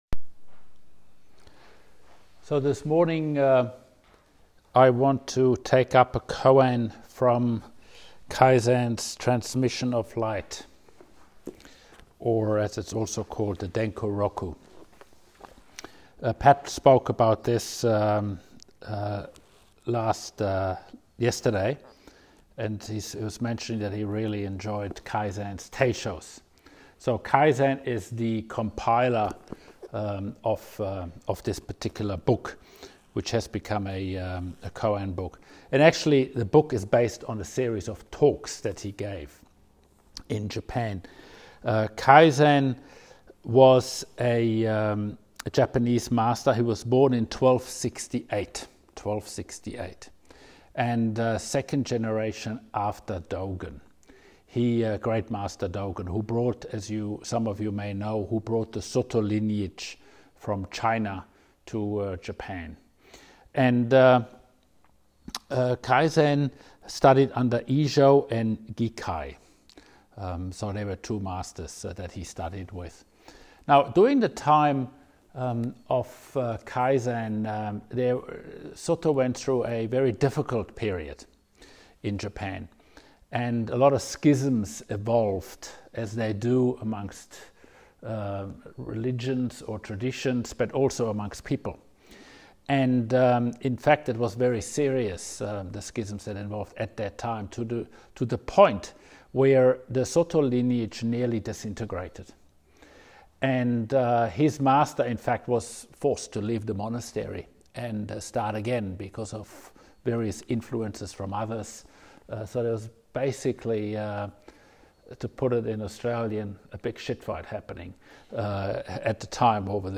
Teisho
at the 2021 Pathway Zen Sesshin at Highfields, QLD, Australia.